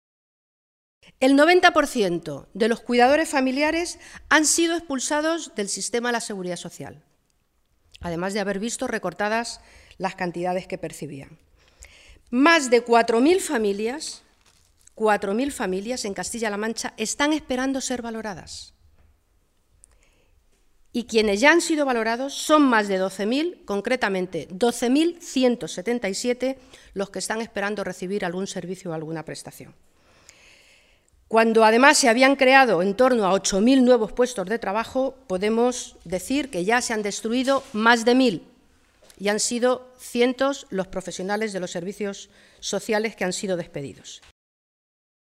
Guadalupe Martín, diputada Nacional del PSOE
Cortes de audio de la rueda de prensa